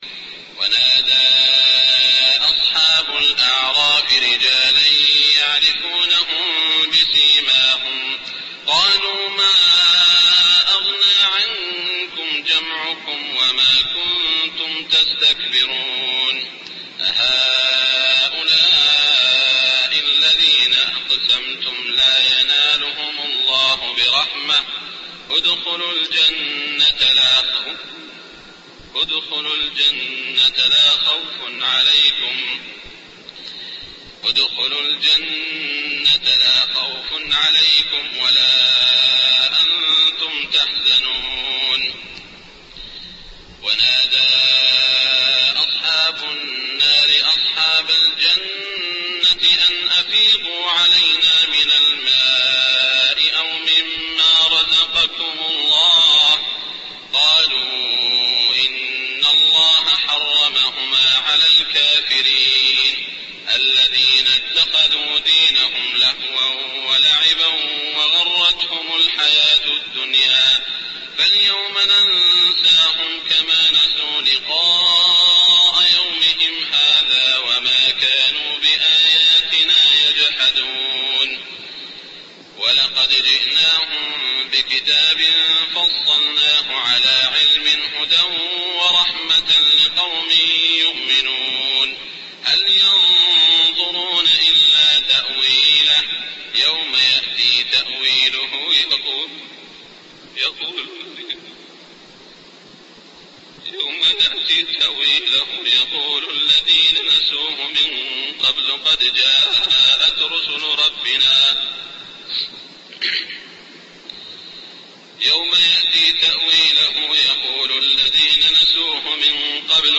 صلاة الفجر 1420 من سورة الأعراف > 1420 🕋 > الفروض - تلاوات الحرمين